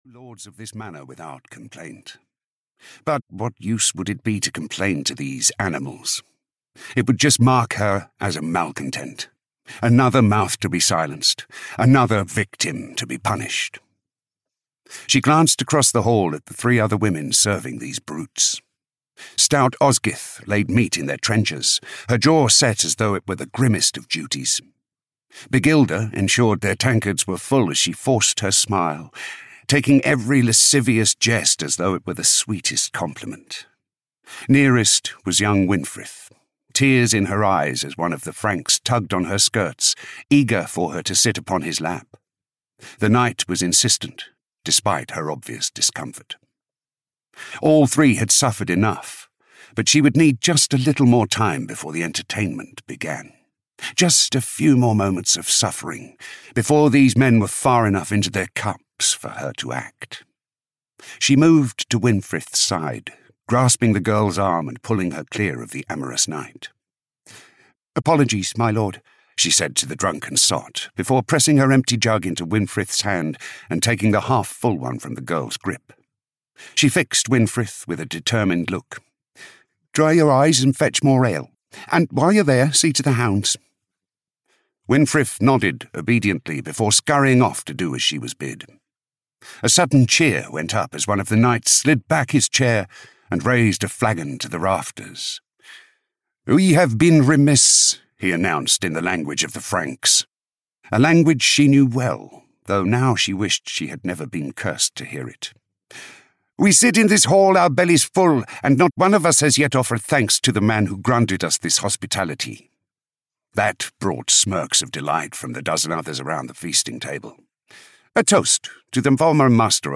Winter Warrior (EN) audiokniha
Ukázka z knihy